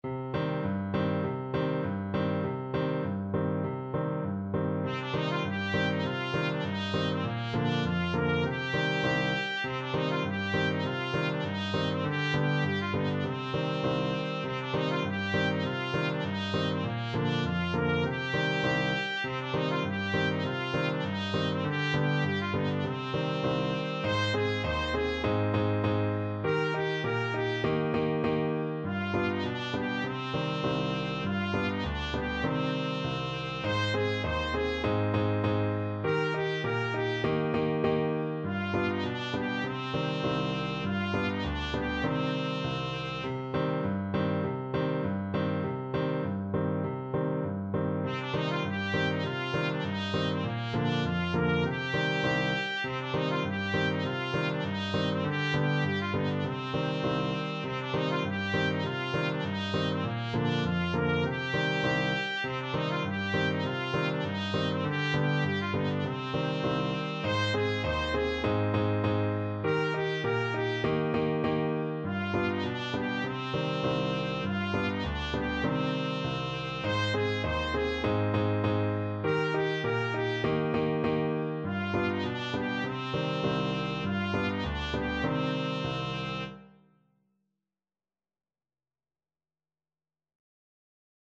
La Mariposa Free Sheet music for Trumpet
Time Signature: 4/4
Tempo Marking: Moderato Score Key: C minor (Sounding Pitch)
Style: Traditional